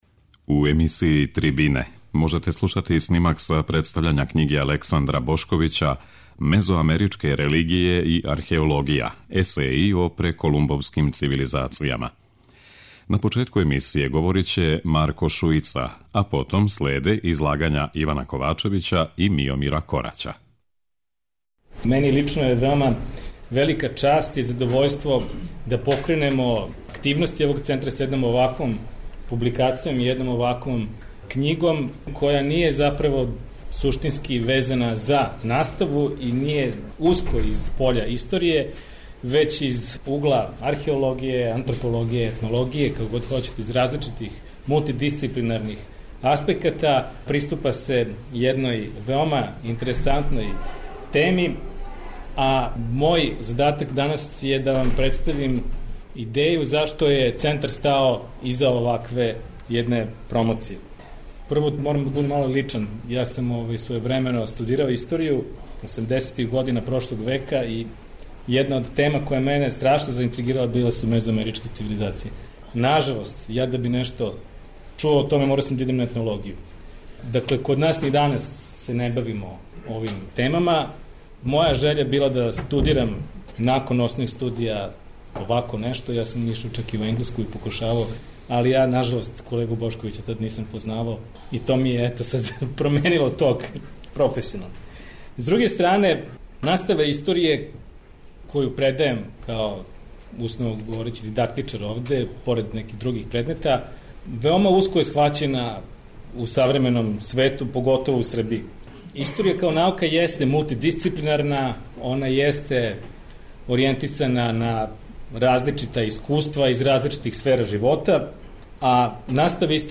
Снимак је забележен 13. априла на Филозофском факултету у Београду.